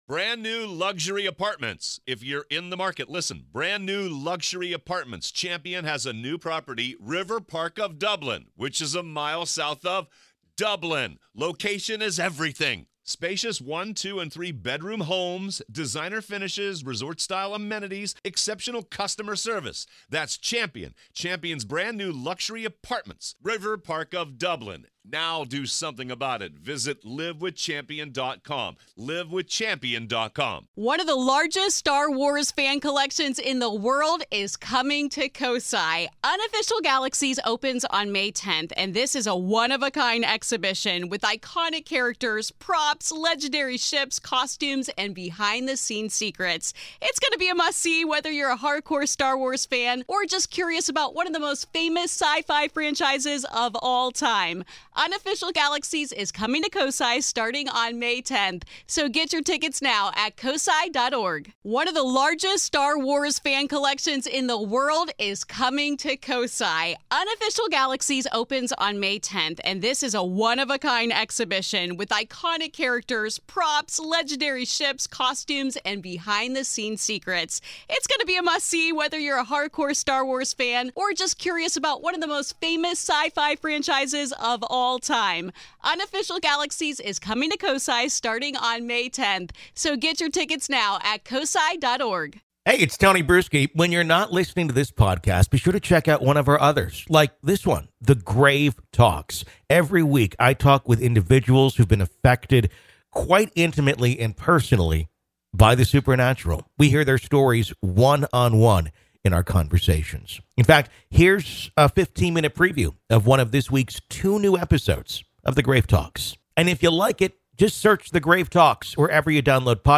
AI Of The Afterlife | Interview